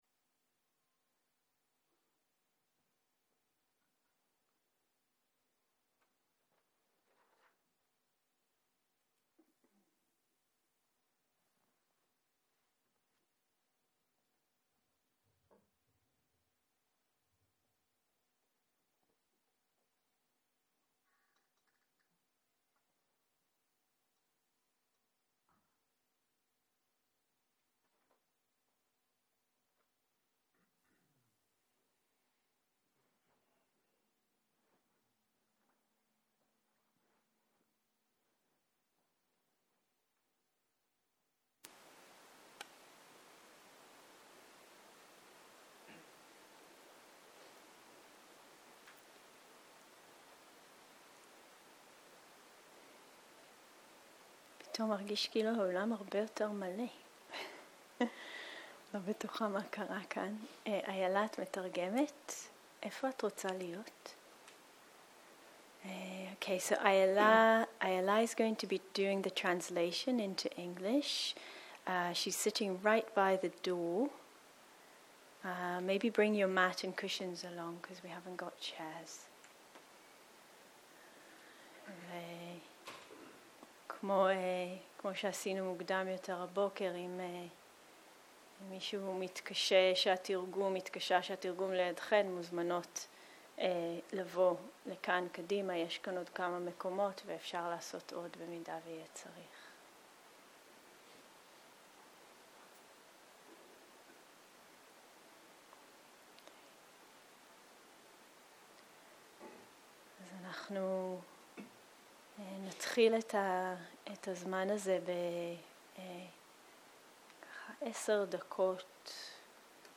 ערב - שיחת דהרמה - התבוננות בחוויה
סוג ההקלטה: שיחות דהרמה